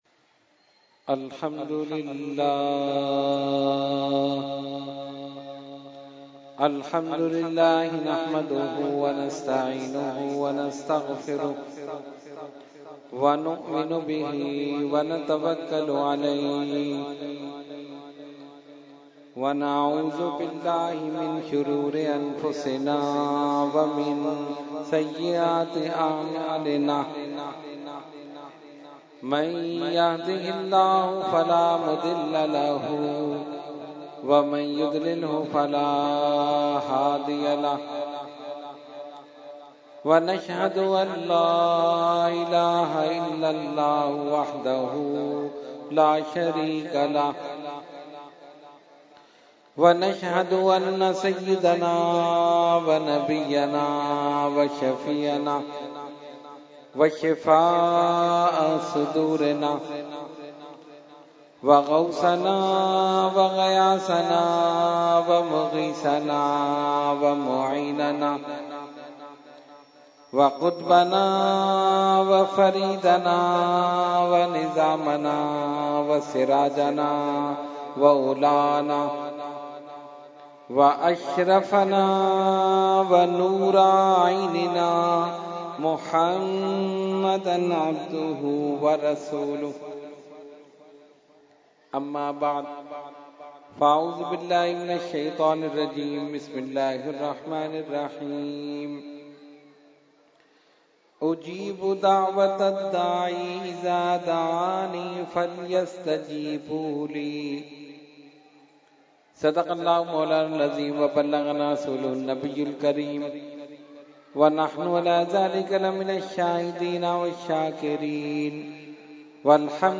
Category : Speech | Language : UrduEvent : Muharram 2018